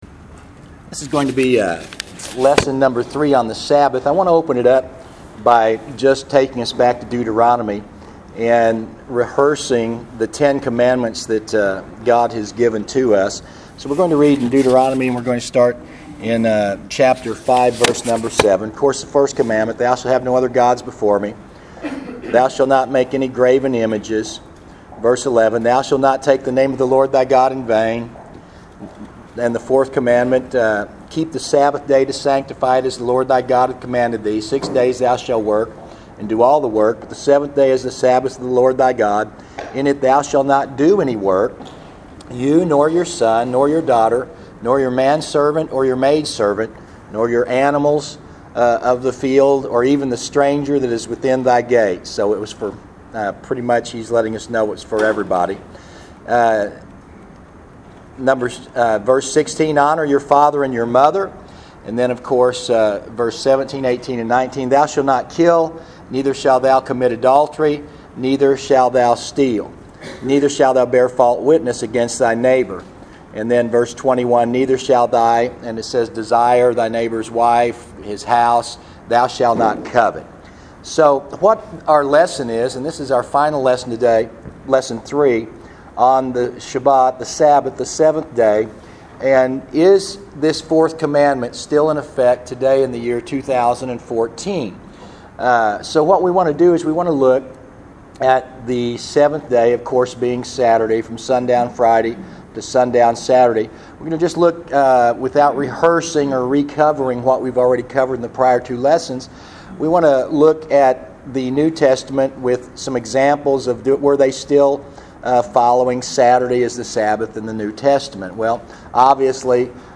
Sabbath: Lesson Three